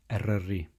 Snowdonia, or Eryri (Welsh: [ɛrəri]